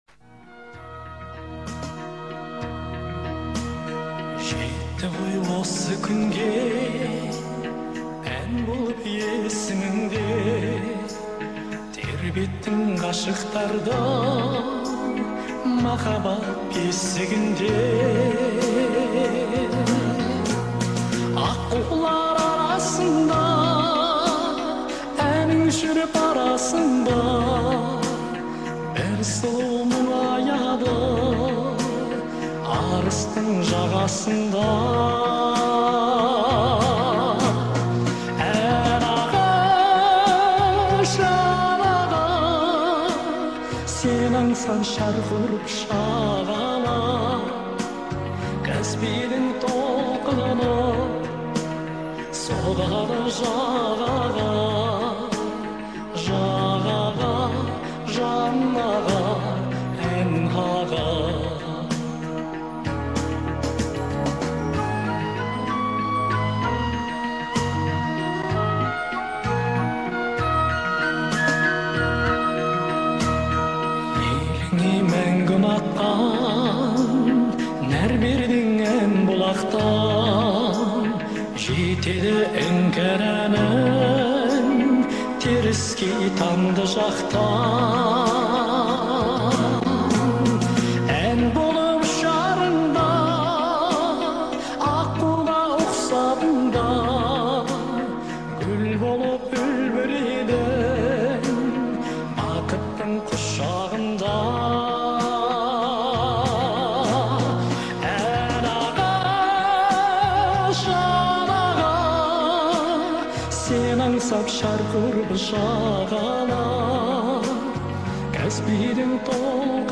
проникновенный вокал